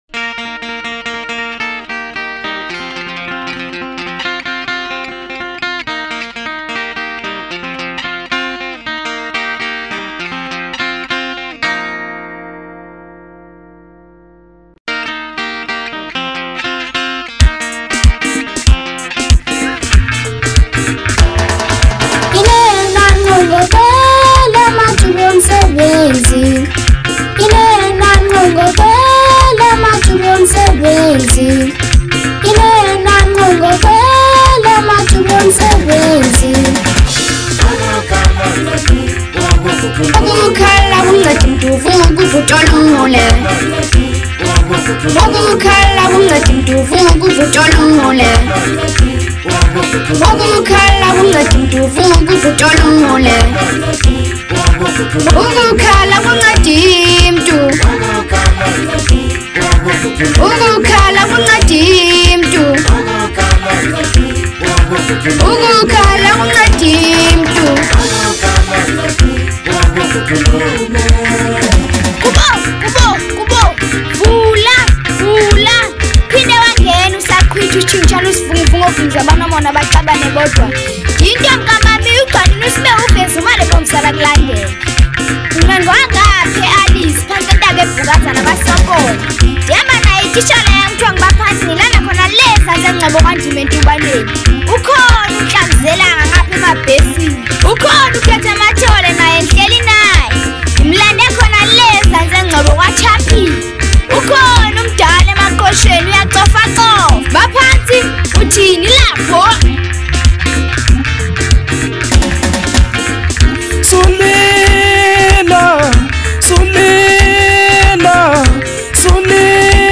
04:30 Genre : Maskandi Size